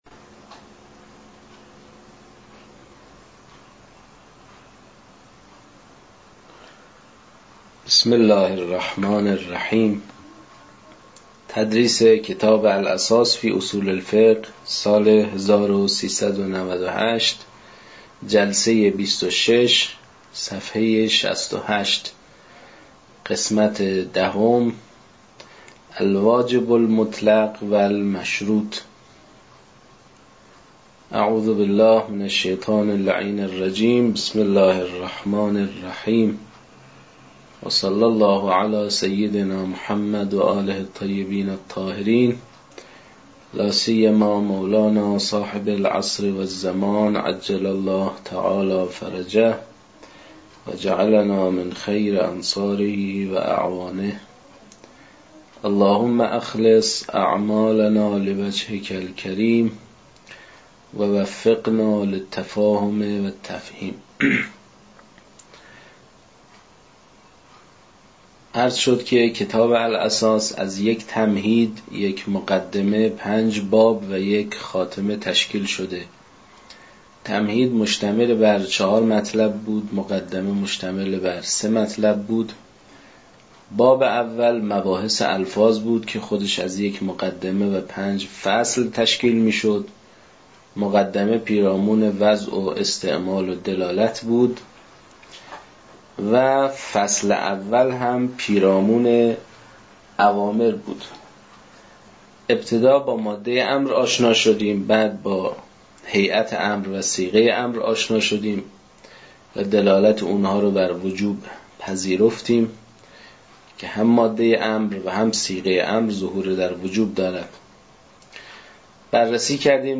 در این بخش، کتاب «الاساس» که اولین کتاب در مرحلۀ آشنایی با علم اصول فقه است، به صورت ترتیب مباحث کتاب، تدریس می‌شود. صوت‌های تدریس
در تدریس این کتاب- با توجه به سطح آشنایی کتاب- سعی شده است، مطالب به صورت روان و در حد آشنایی ارائه شود.